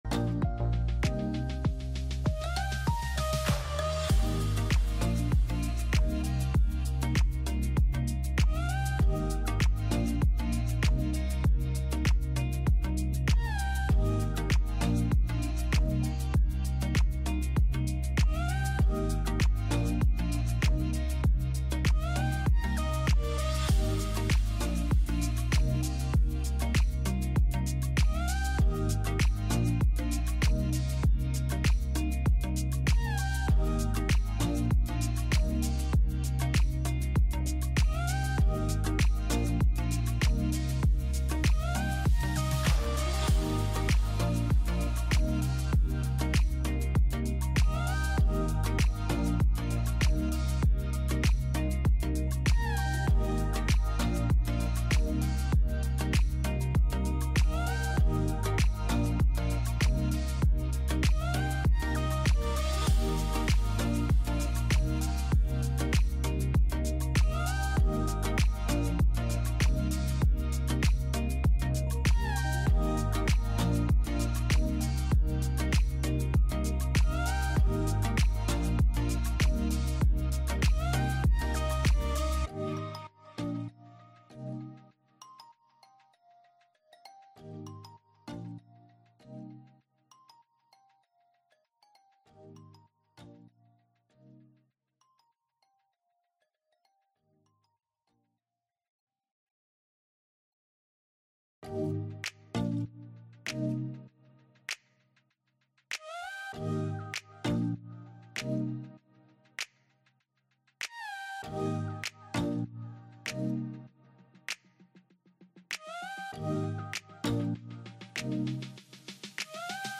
Sesión Pública del Pleno del Tribunal Estatal Electoral de Guanajuato